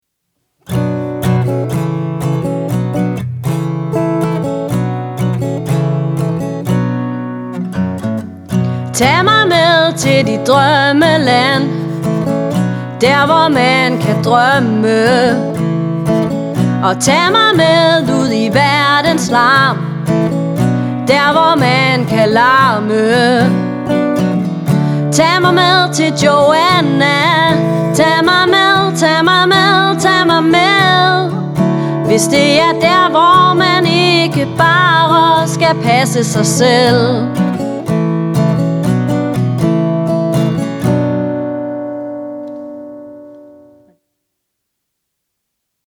Musikken er rolig og afslappet.